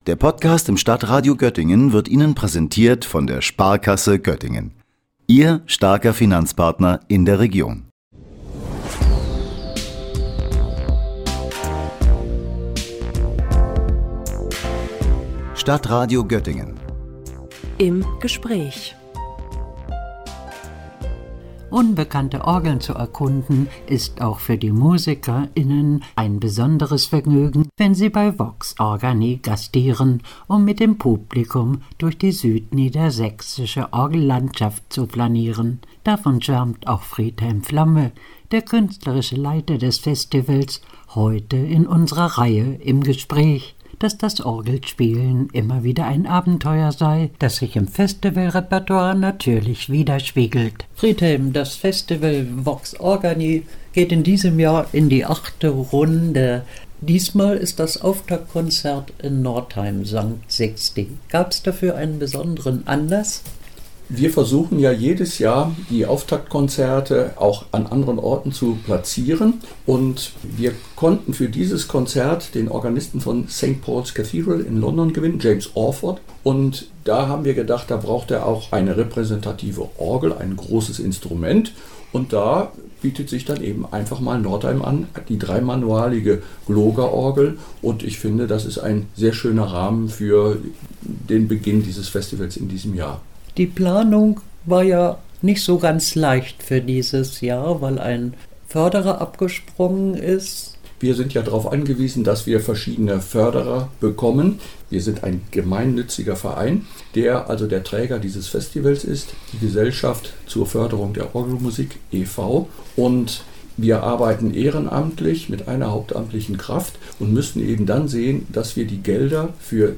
Das Orgelfestival „Vox Organi“ – Gespräch